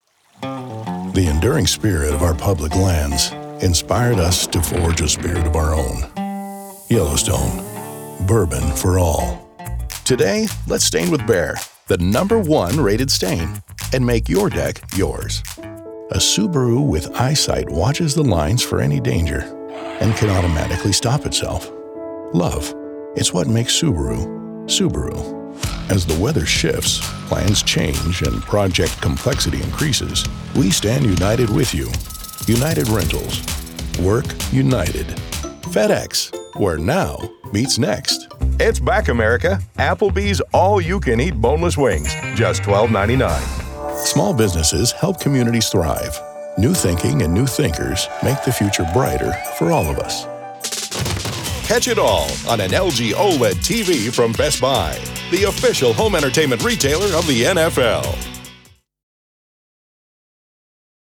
My voice is in the lower mid range and has a natural warmth. I project a natural and sincere tone that works with various attitudes from friendliness to confident coolness....
English (North American) Adult (30-50)